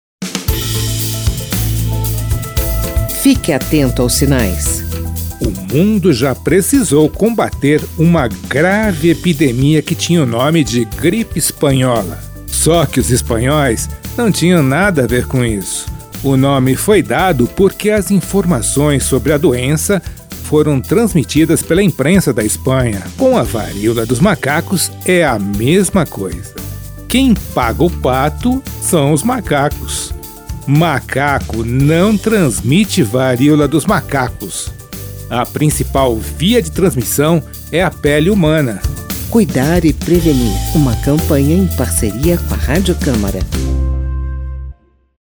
Texto e locução